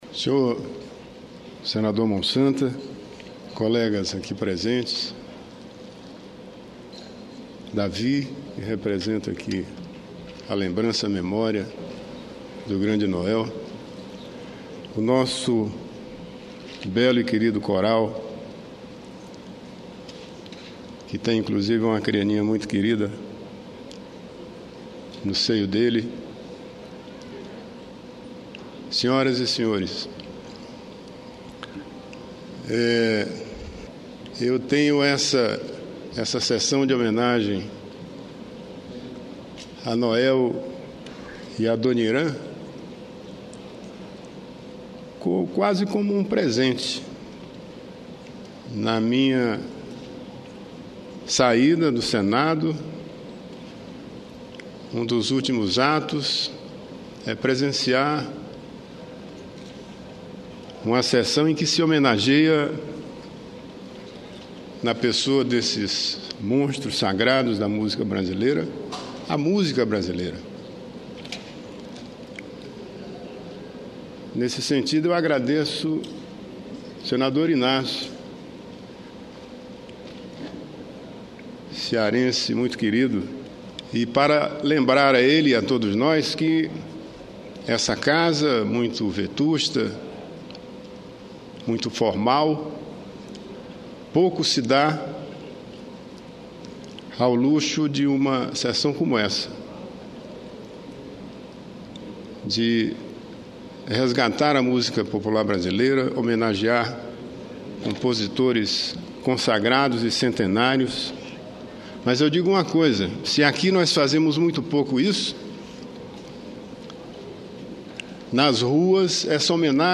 Discurso do senador Geraldo Mesquita Júnior